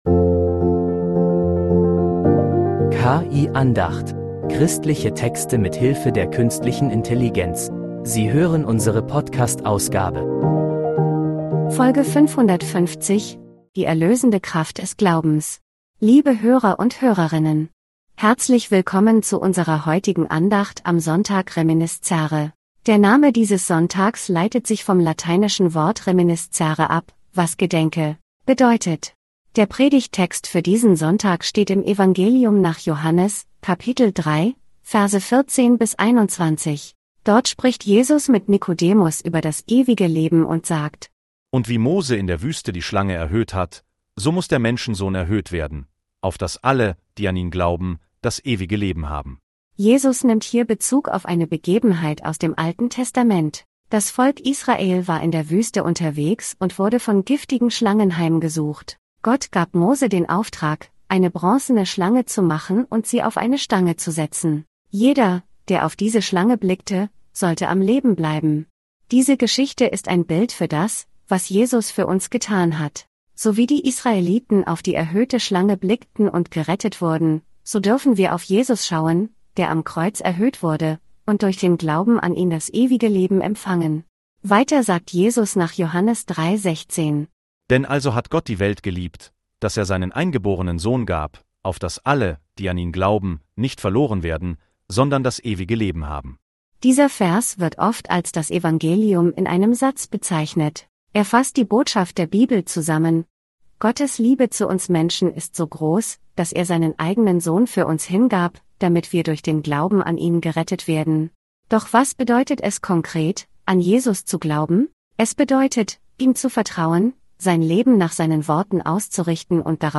Andacht.